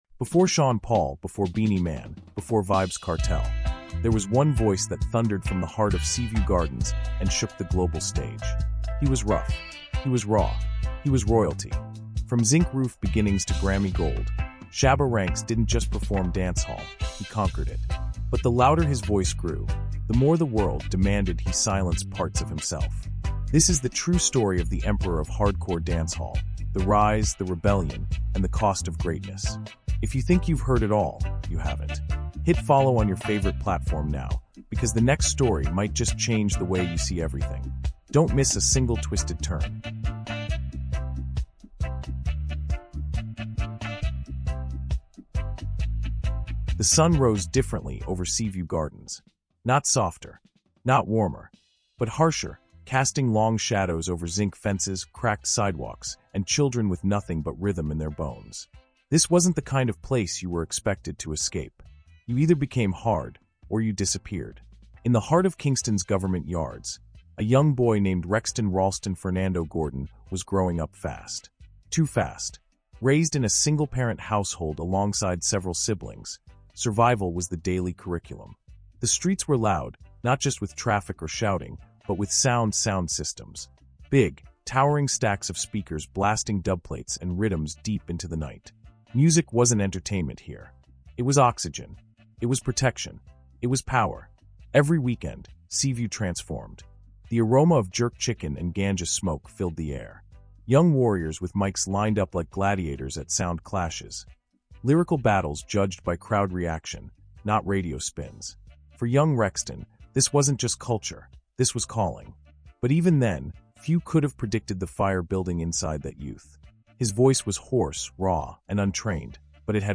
CARIBBEAN HISTORY: Shabba Ranks — The Emperor of Hardcore Dancehall is a powerful, immersive, fact-based audiobook documentary chronicling the rise, reign, and cultural legacy of Rexton Rawlston Fernando Gordon — better known as Shabba Ranks. From the zinc-roofed corners of Seaview Gardens to Grammy stages, Shabba blazed a trail that redefined Caribbean music, reshaped black masculinity, and influenced global pop culture.